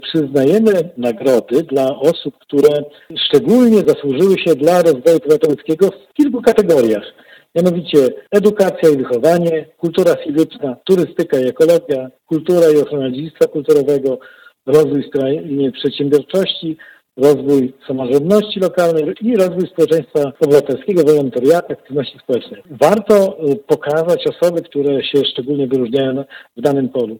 Marek Chojnowski – starosta powiatu ełckiego.